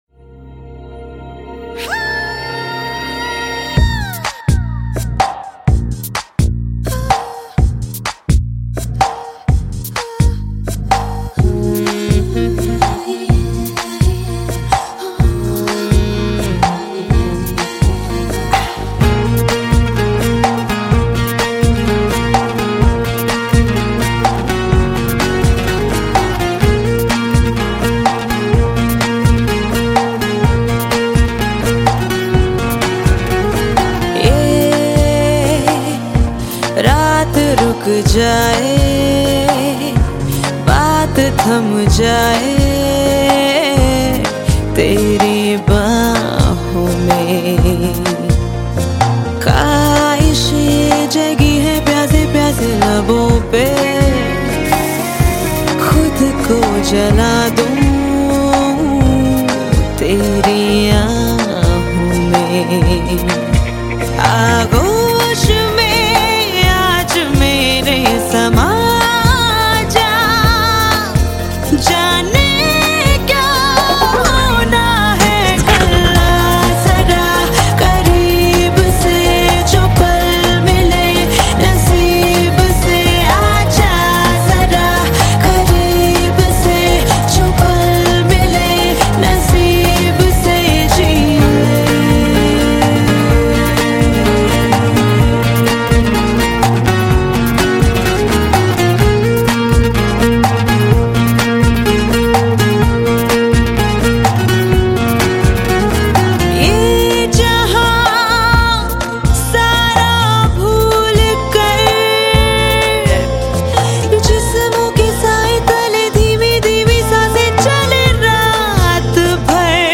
Home » Bollywood Mp3 Songs » Bollywood Movies